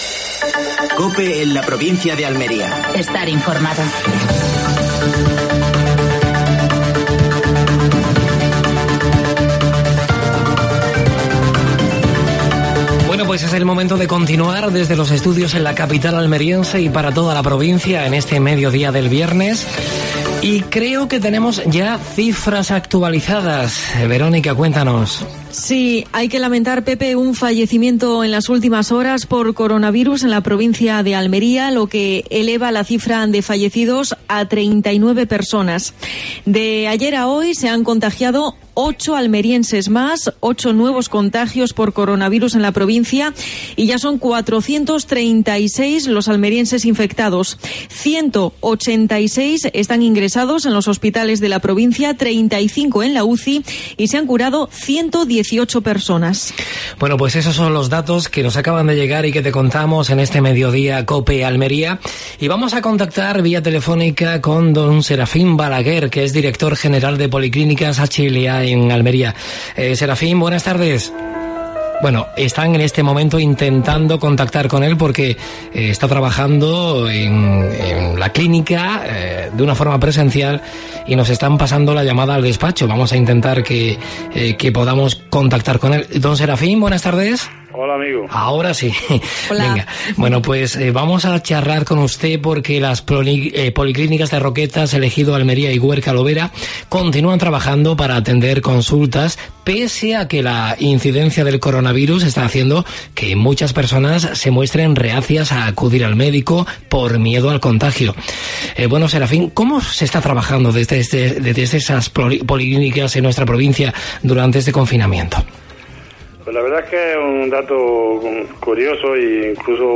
Actualidad en Almería. Un fallecido en las últimas 24 horas en la provincia por coronavirus. Entrevista